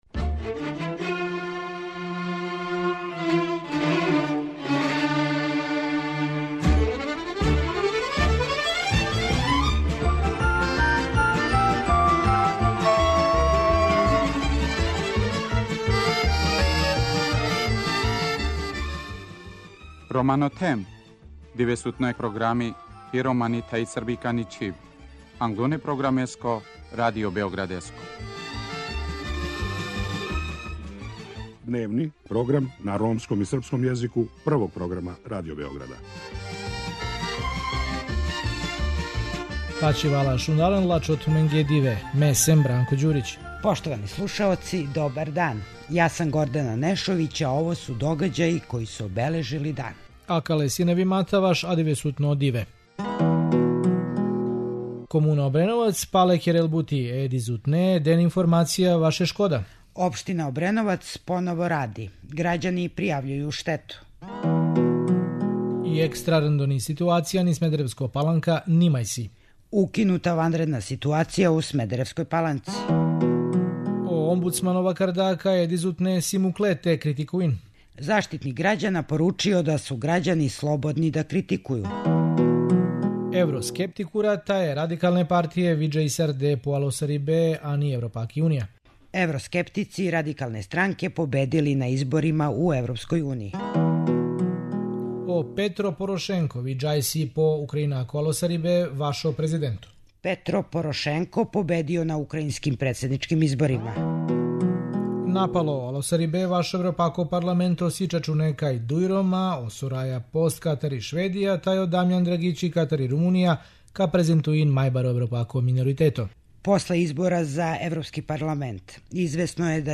Чућете репортажу коју смо направили у Хали Пионир где тренутно борави око 400 особа евакуисаних са поплављеног подручја Обреновца.